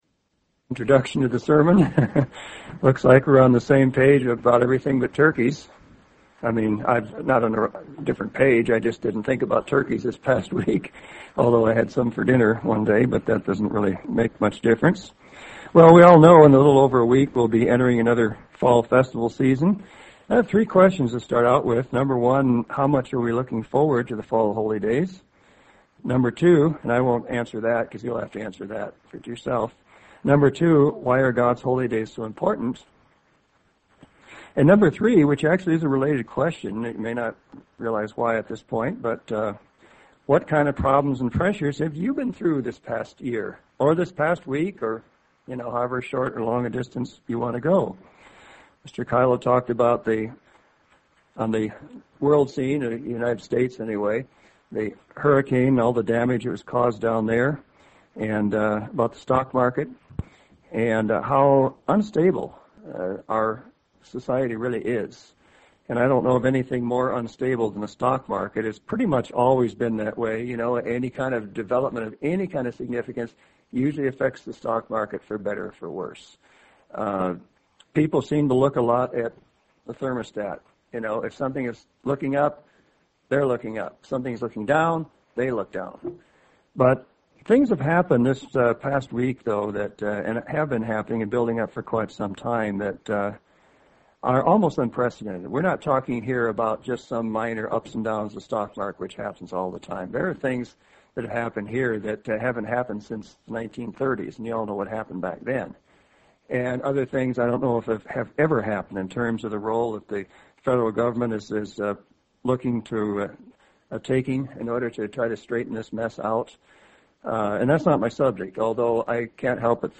UCG Sermon Notes 9-20-08 Bend Holy Days Keep Us Focused 10-4-08 Roseburg, Medford Introduction – In a little over a week, we will be entering another fall Festival season.